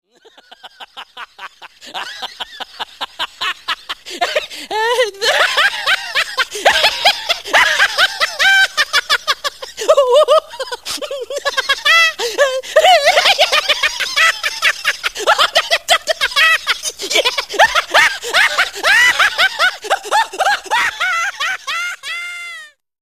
laughter_audio.mp3